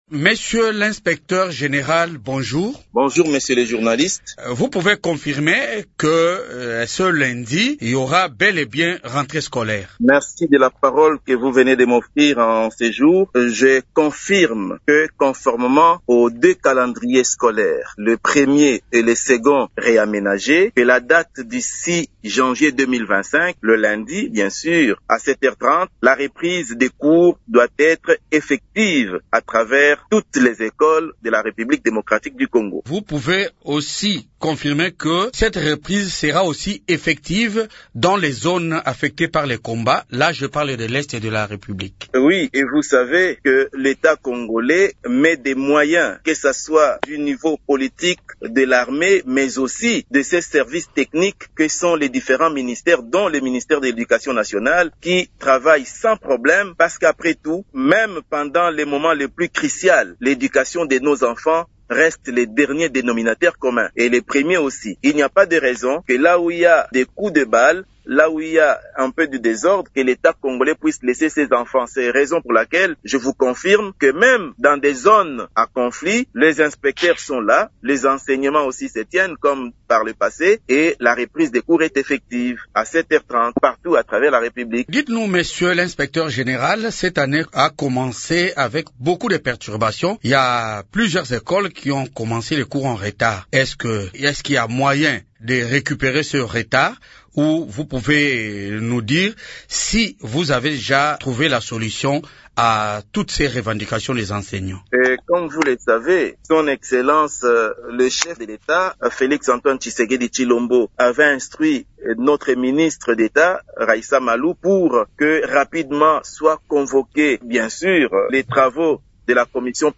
Invité de Radio Okapi, il assure que cette mesure est d’application même dans les zones affectées par les combats (NDLR : les territoires sous occupation du M23).